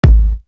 Latin Thug Kick 1.wav